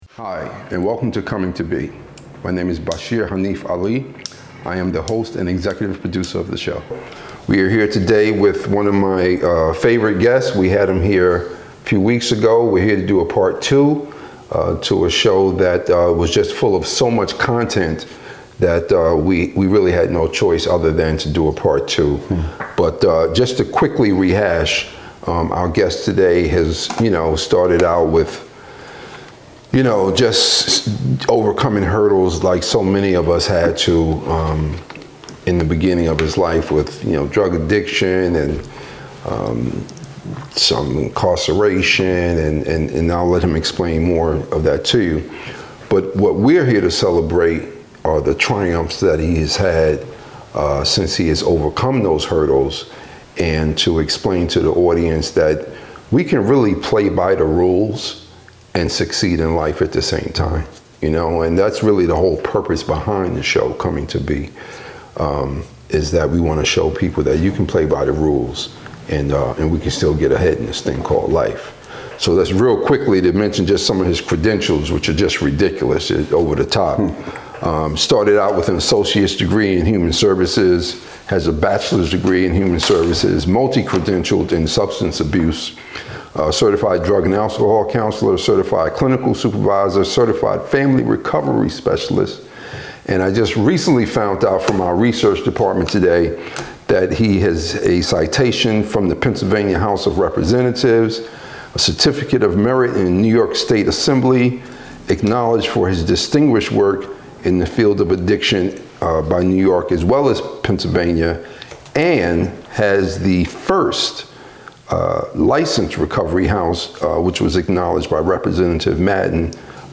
featuring a wide range of inspiring guests, Coming to B aims to carry a message of hope by interviewing ordinary people who have accomplished extraordinary things.